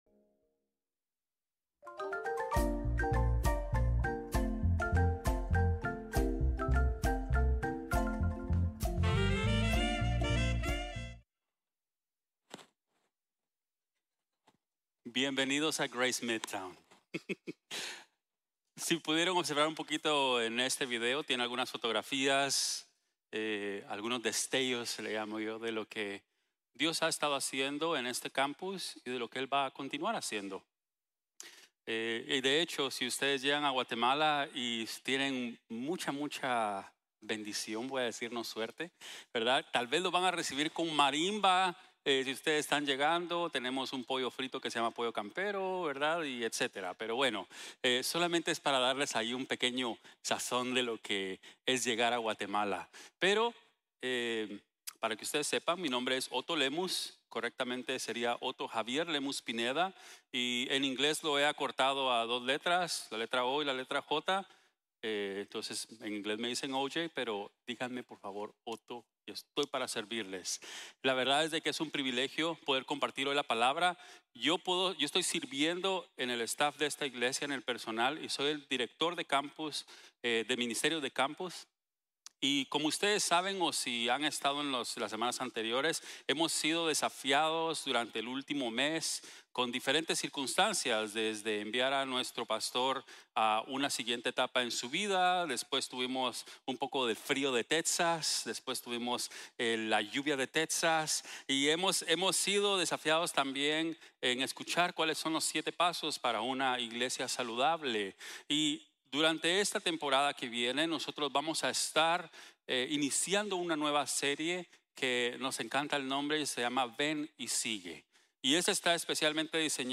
Ven y Sigue | Sermon | Grace Bible Church